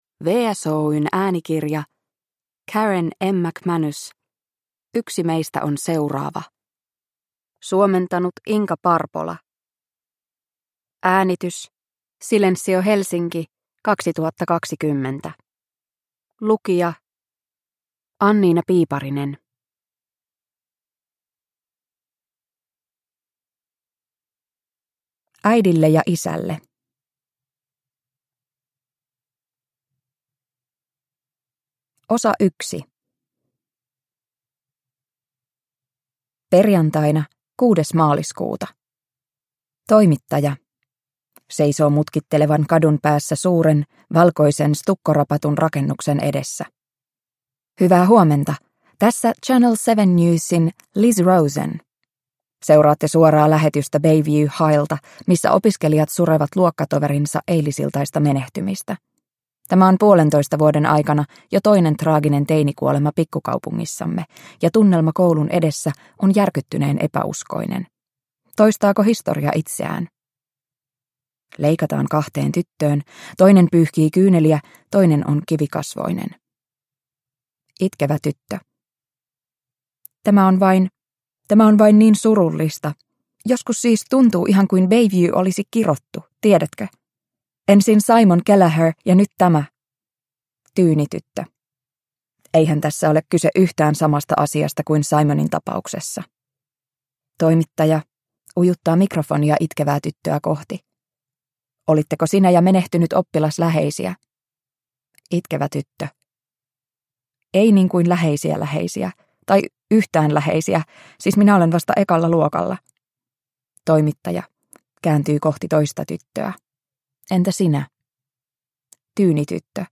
Yksi meistä on seuraava – Ljudbok